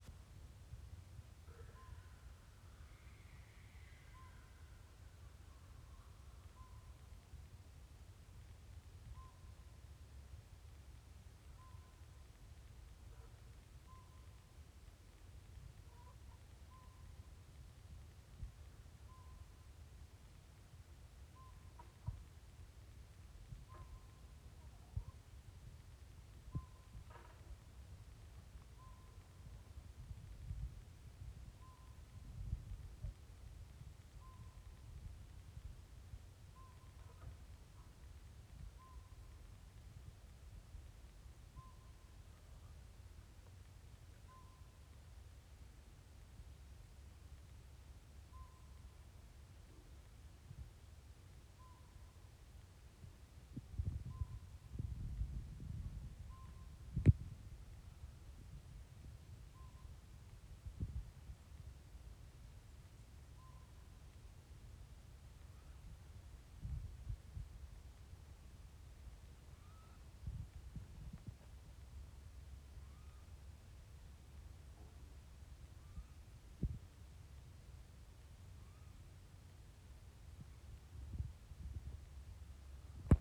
Pygmy owl